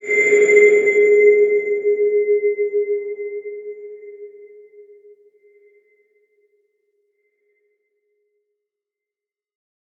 X_BasicBells-G#2-pp.wav